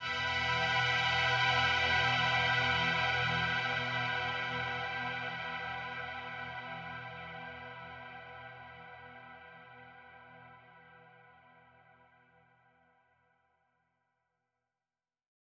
VTS1 Selection Kit 140BPM Atmo FX.wav